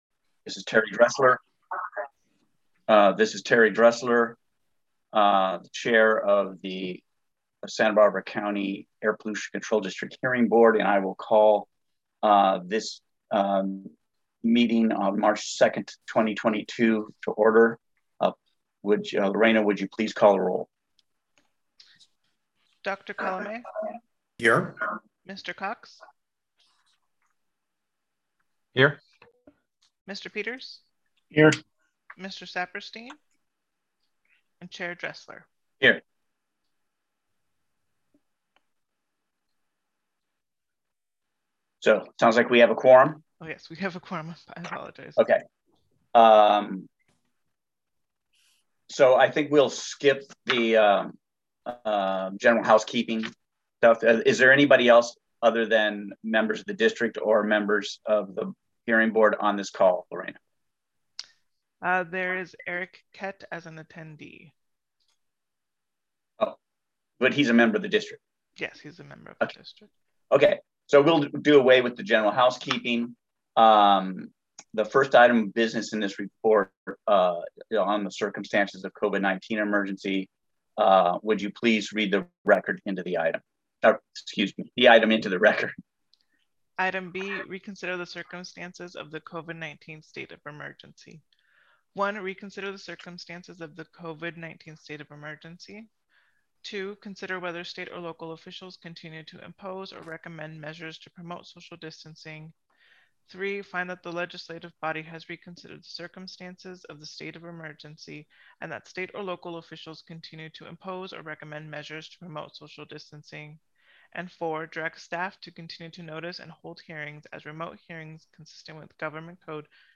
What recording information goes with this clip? *REMOTE VIRTUAL PARTICIPATION ONLY